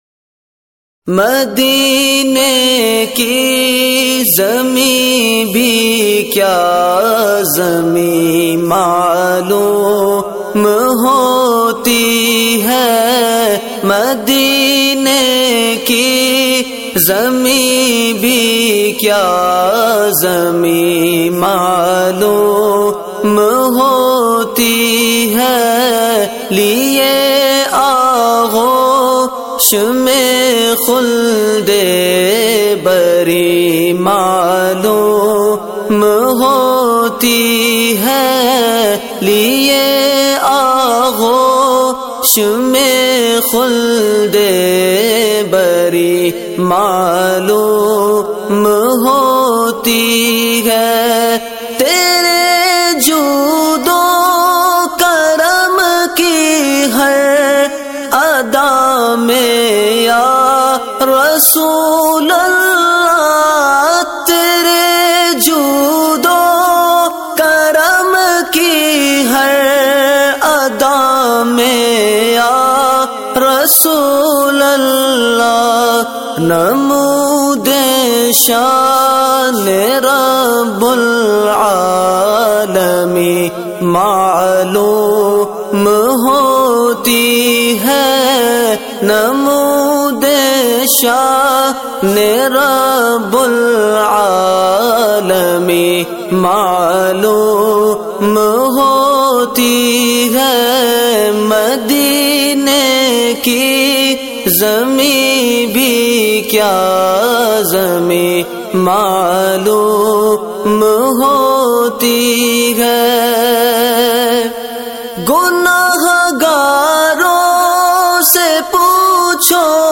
Category : Naat | Language : UrduEvent : 10th Album